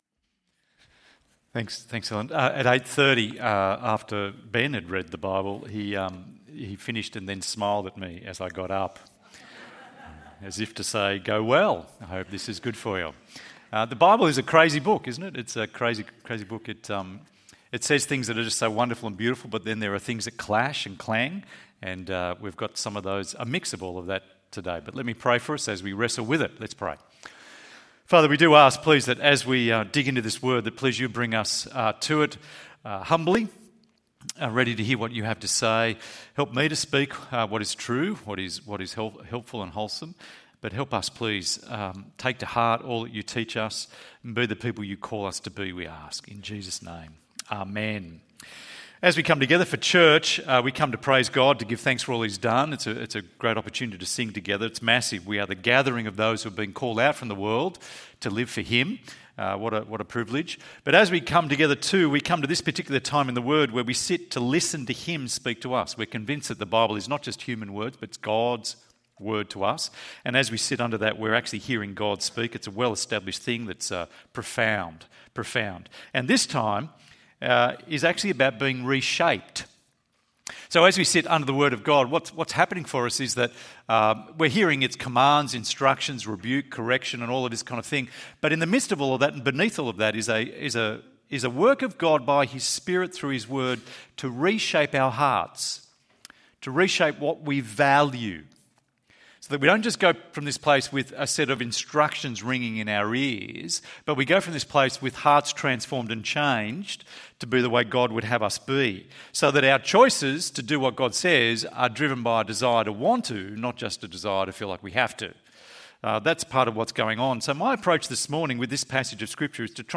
Individualised Love ~ EV Church Sermons Podcast